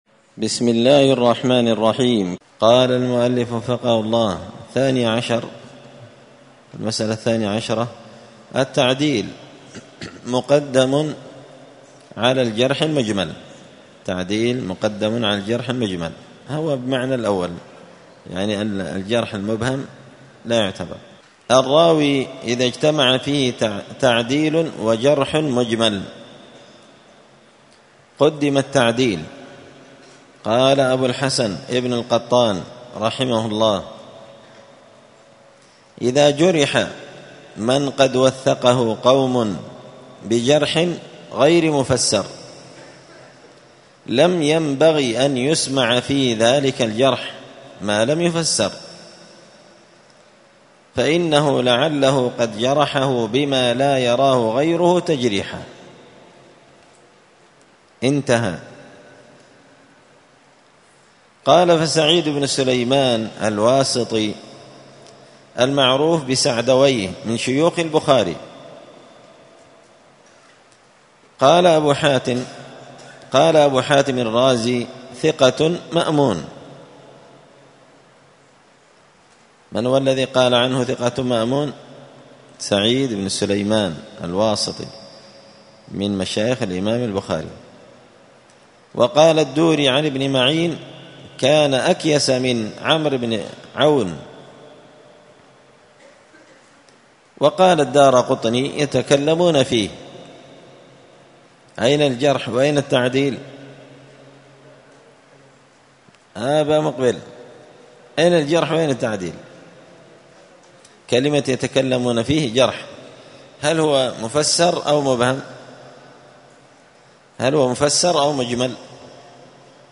*الدرس الثامن عشر (18) التعديل مقدم على الجرح المجمل*